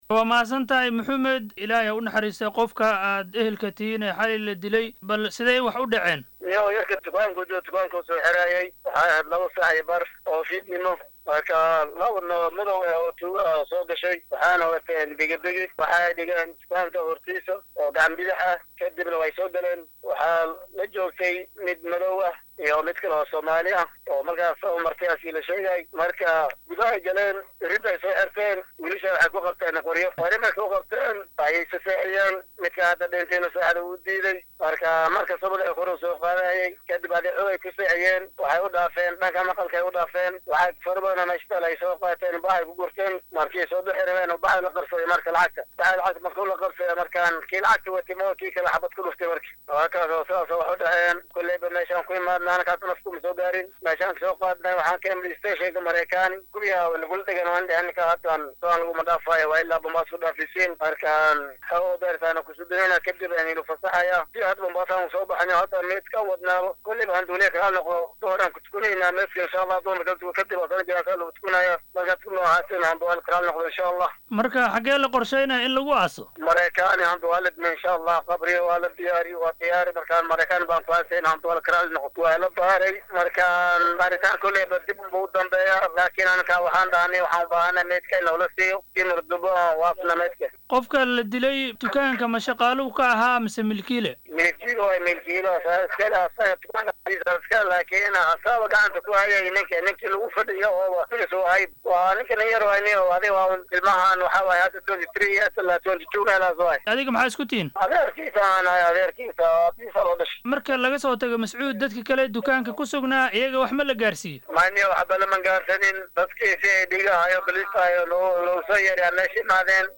Wareysiga-dilka-Mariakani-.mp3